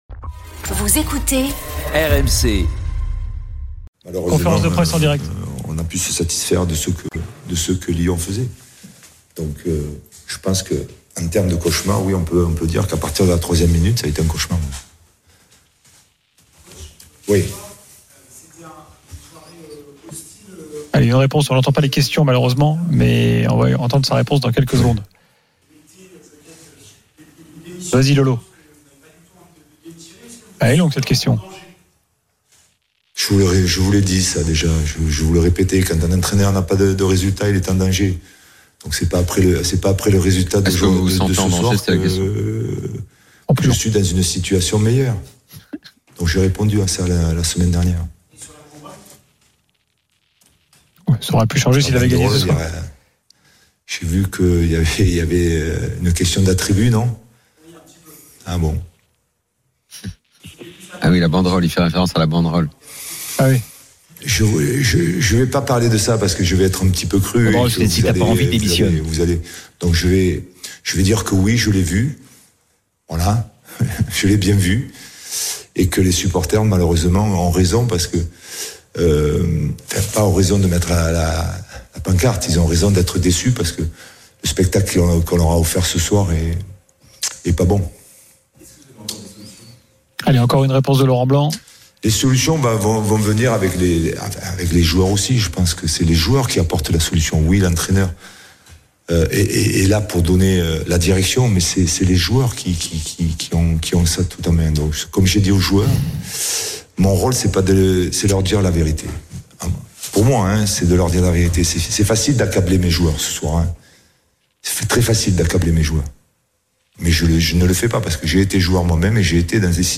Le Top de l'After Foot : Les premiers mots de Laurent Blanc en conférence de presse après la claque reçue par le PSG – 03/09